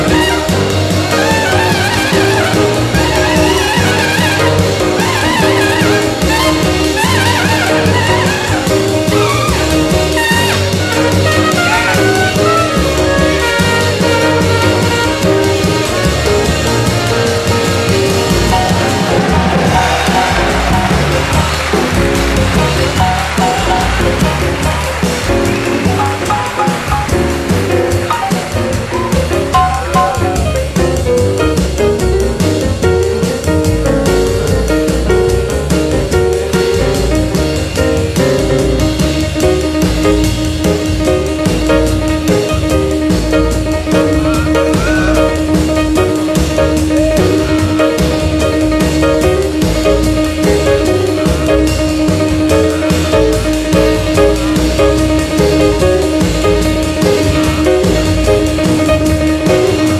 ROCK / S.S.W./A.O.R. / FOLK / BLUE GRASS / CELTIC / COUNTRY